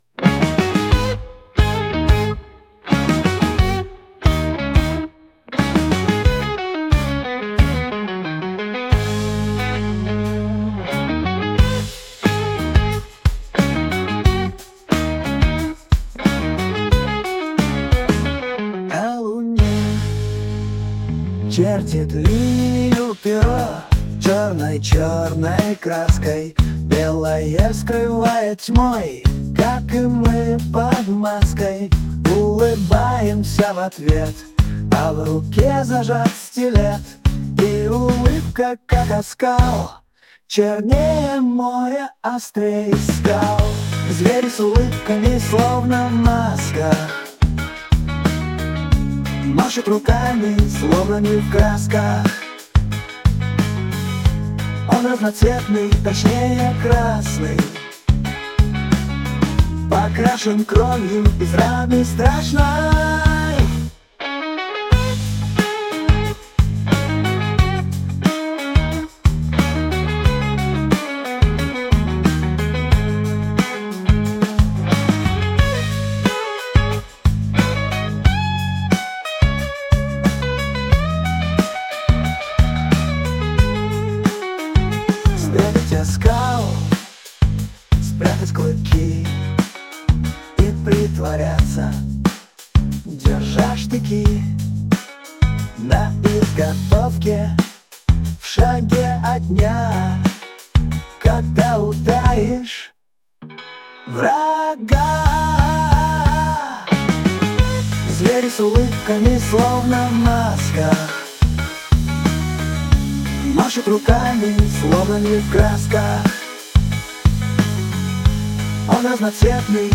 Psychedelic rock, tragic melodious melody